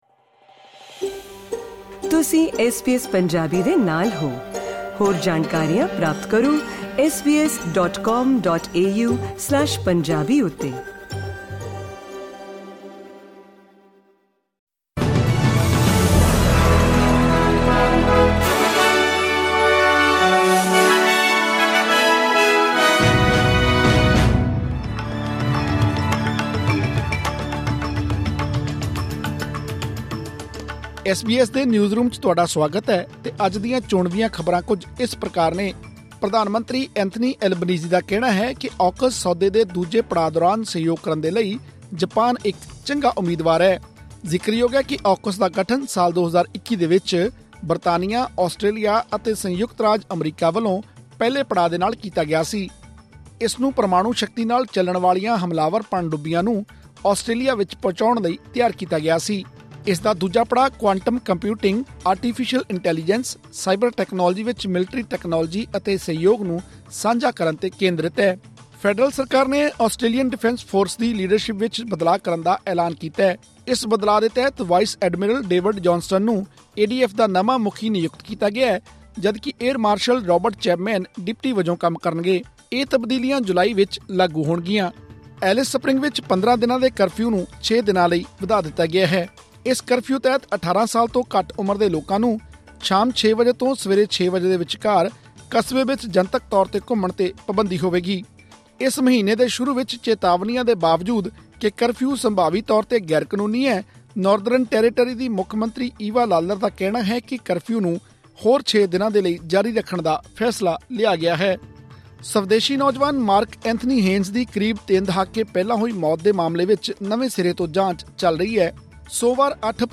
ਐਸਬੀਐਸ ਪੰਜਾਬੀ ਤੋਂ ਆਸਟ੍ਰੇਲੀਆ ਦੀਆਂ ਮੁੱਖ ਖ਼ਬਰਾਂ: 9 ਅਪ੍ਰੈਲ, 2024